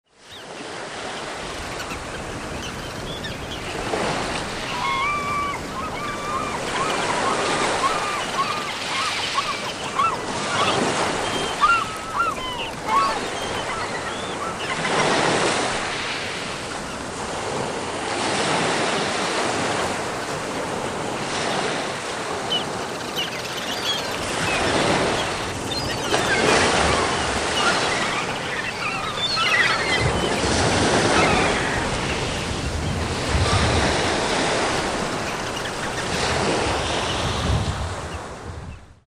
North Sea Relaxation - sound of the sea, piano, crushing waves, seagulls, sounds to calm down and relax - audiobook mp3 download
Audio Auszug / Vorschau North Sea atmosphere pure: the harbour, fisher, boats, water 08:42 min